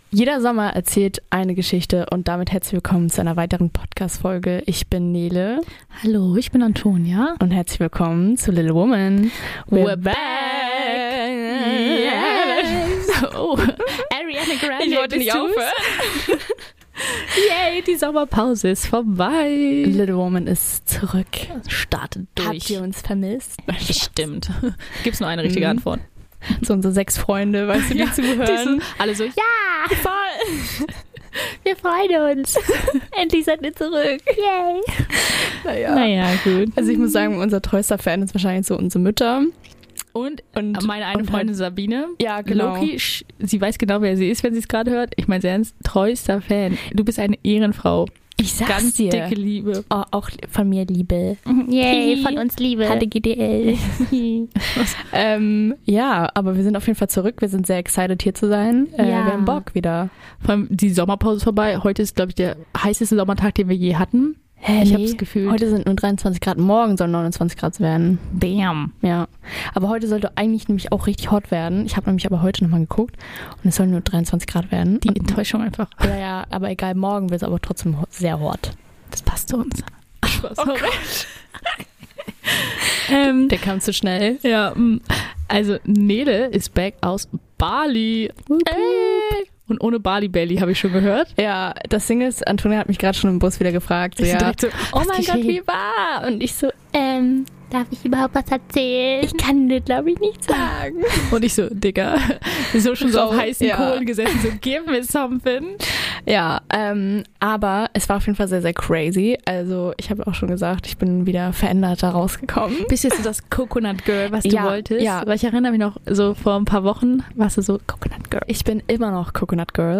In den freien Wochen sind natürlich wieder spannende Dinge passiert und diese werden unter vielen Lachen und Staunen erzählt.